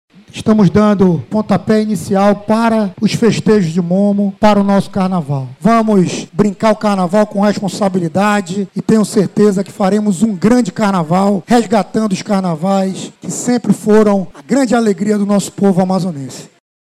As apresentações começam às 19h, com a apoteose dos dois bois, como explica o secretário de cultura, Caio André.